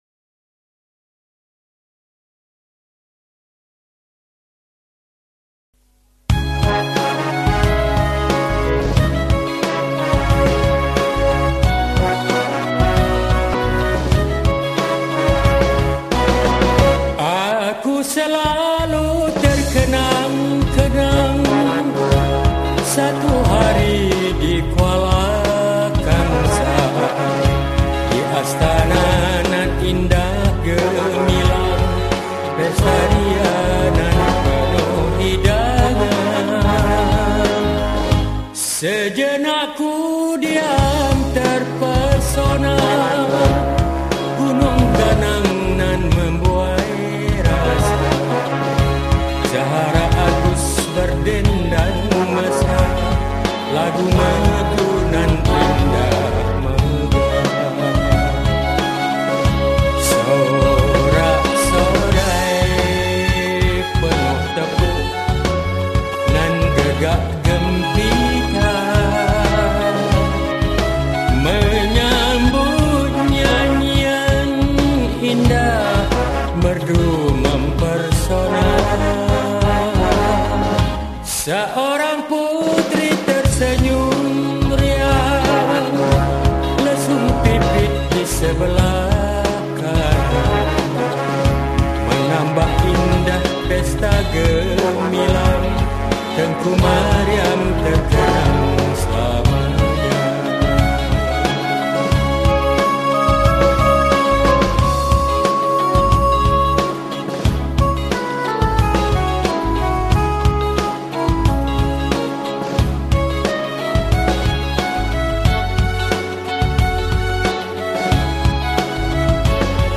Malay Songs , Patriotic Songs